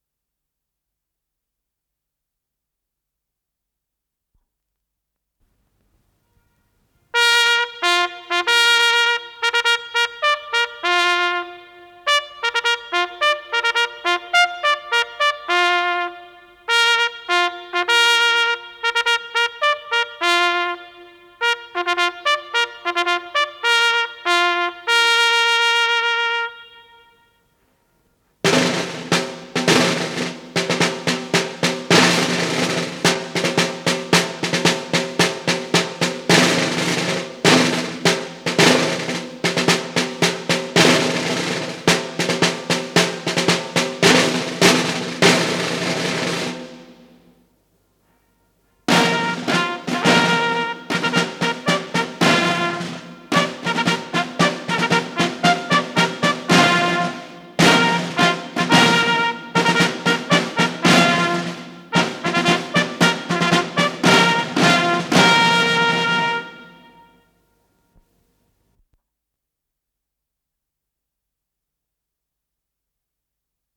труба
ударные
Скорость ленты38 см/с